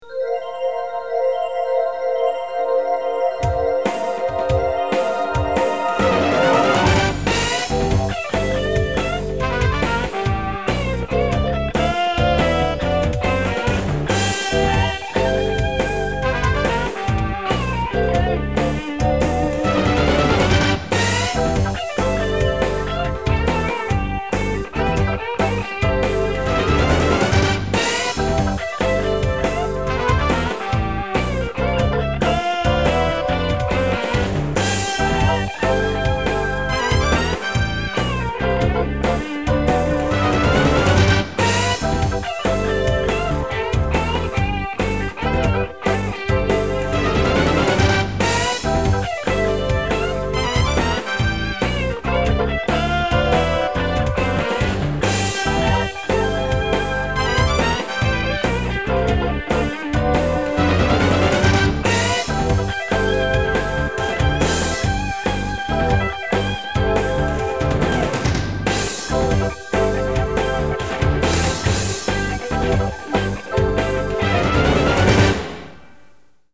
Guitar and Synthesizer